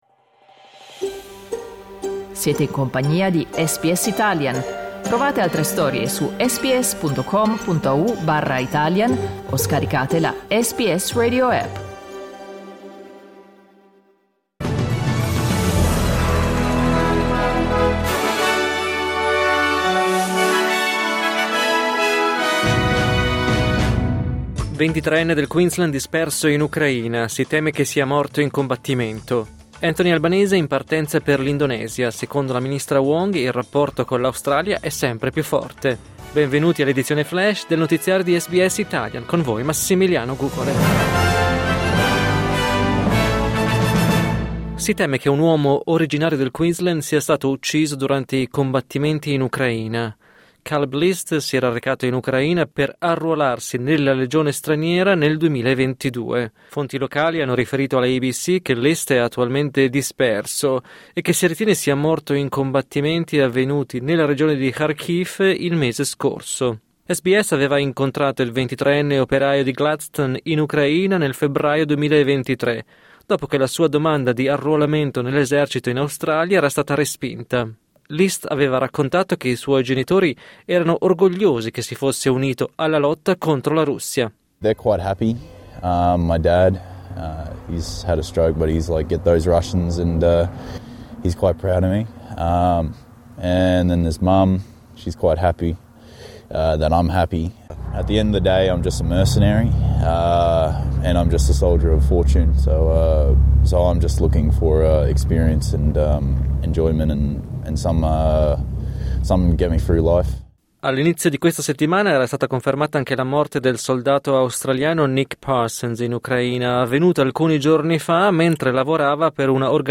News flash mercoledì 14 maggio 2025